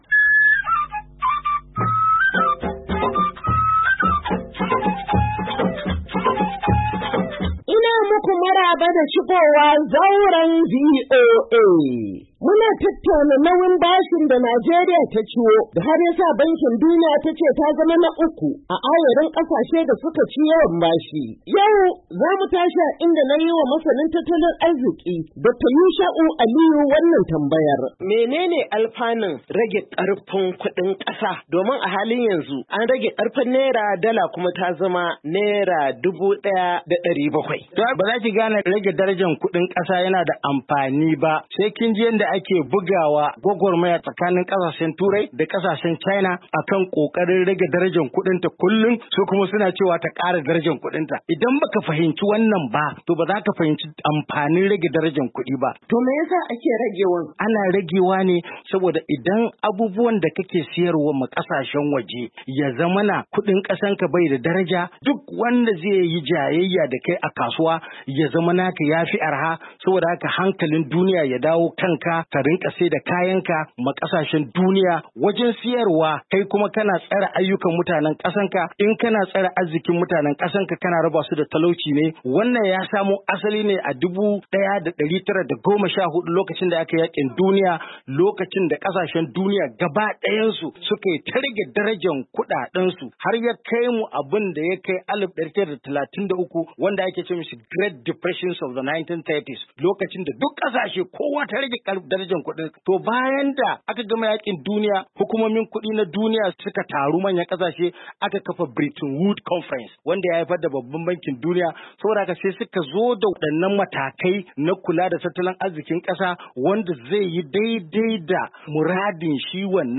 A Wannan shiri za a ji manyan baki, Masanin tattalin arziki